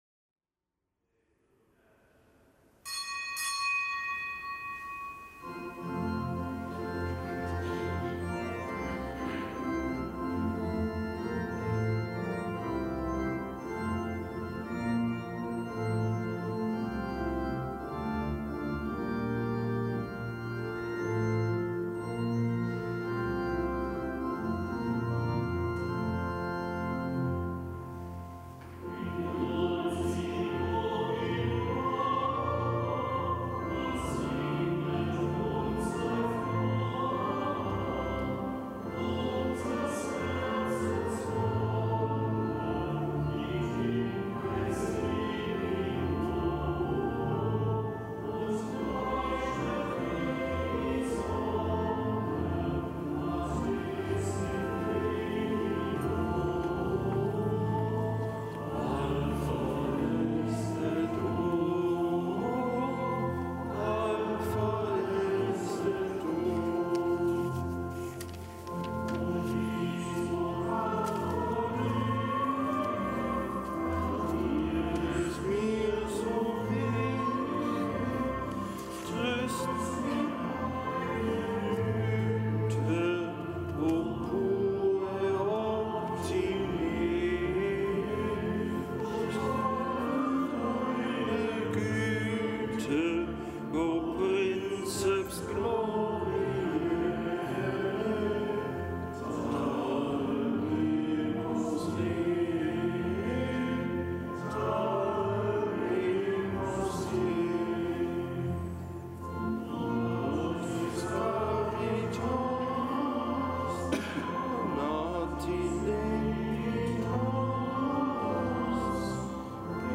Kapitelsmesse aus dem Kölner Dom am fünften Tag der Weihnachtsoktav.